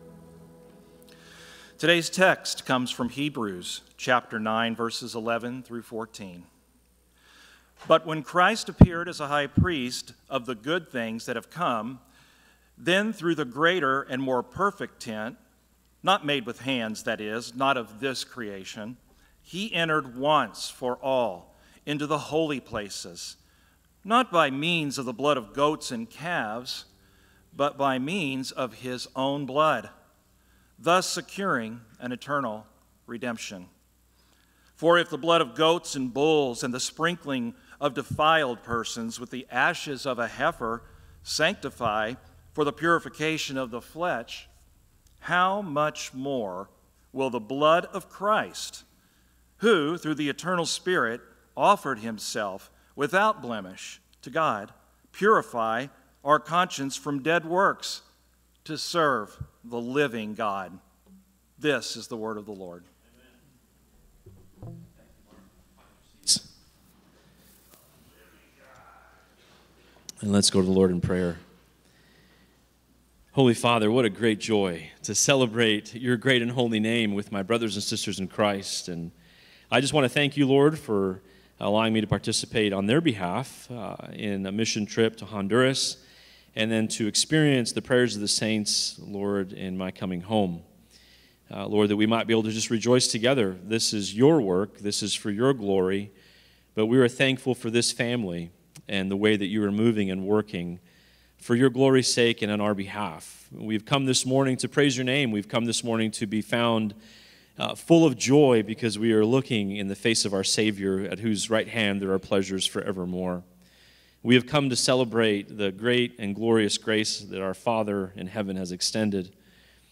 A message from the series "Standalone."